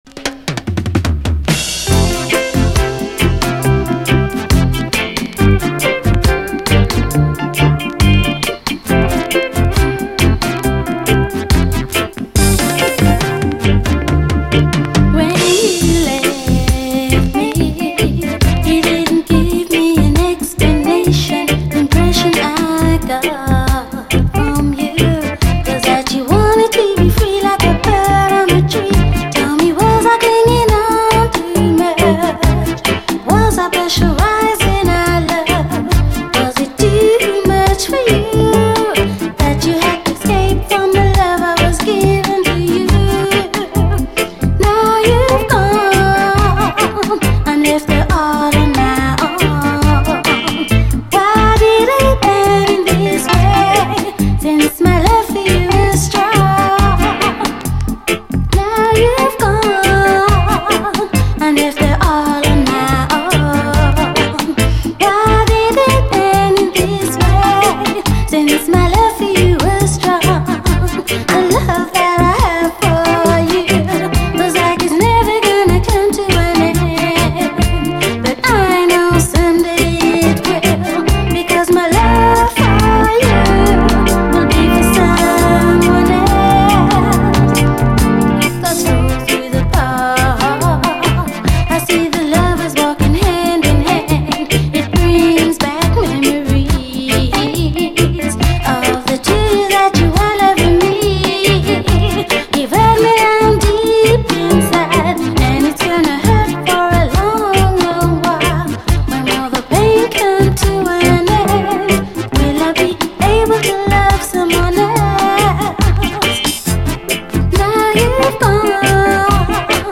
REGGAE
超最高レアUKラヴァーズ！
盤表面的な細かいスレかなり目立ちます。試聴ファイルはこの盤からの録音です
鬼ほど切ないイントロ、儚い歌声が聴こえただけでもうKOされるキラー・チューン！
ビュインビュインと鈍く鳴るシンセ使いもカッコいい。
ジットリと哀感たっぷりでこちらもよい。
両面後半はダブに接続。